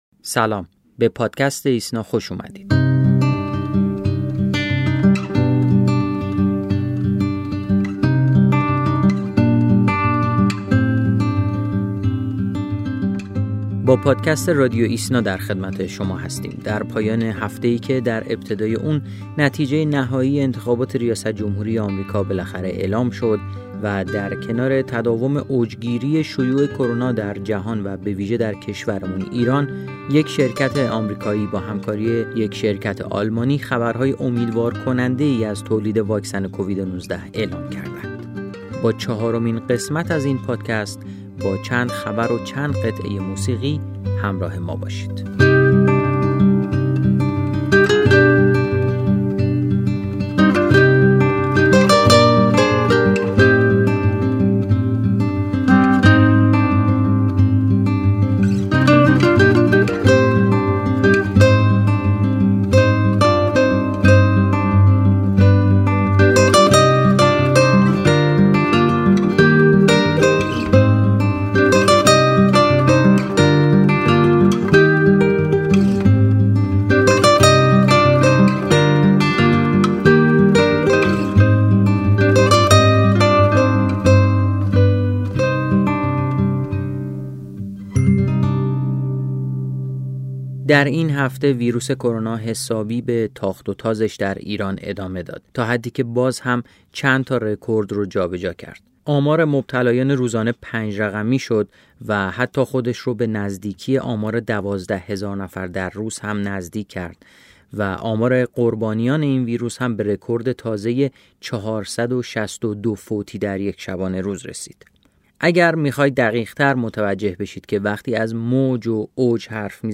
همچنین، در کنار تداوم اوج‌گیری شیوع کرونا در جهان و بویژه در ایران، از سوی دو شرکت آمریکایی و آلمانی خبرهای امیدوارکننده‌ای از تولید واکسن کووید-۱۹ اعلام شده است. با چهارمین قسمت از پادکست رادیوایسنا، با چند خبر و چند قطعه موسیقی، همراه ما باشید.